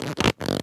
foley_leather_stretch_couch_chair_23.wav